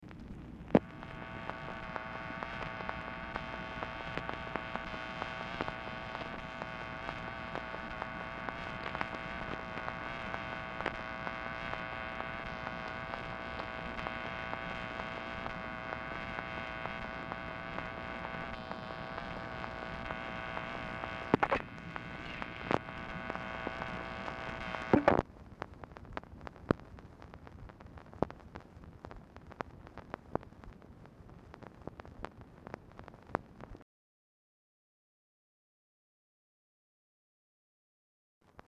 Telephone conversation # 11183, sound recording, MACHINE NOISE, 12/21/1966, time unknown | Discover LBJ
Format Dictation belt
LBJ Ranch, near Stonewall, Texas